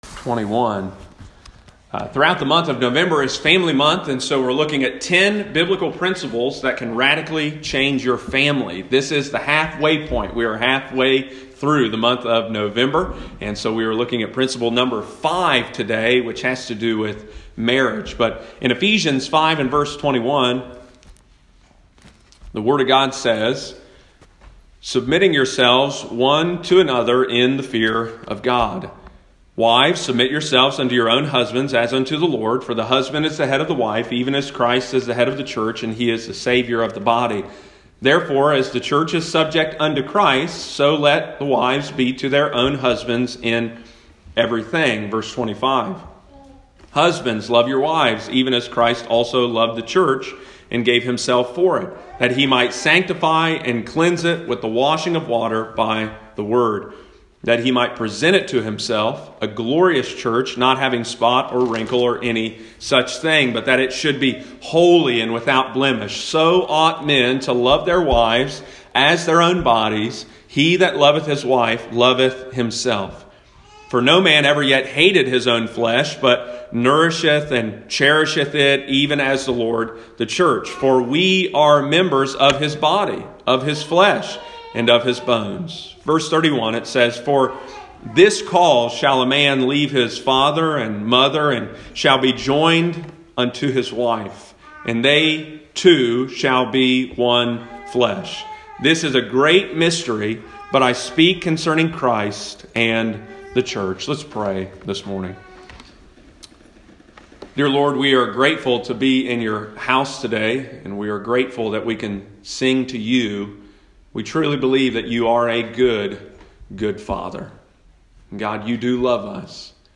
Sunday morning, November 15, 2020.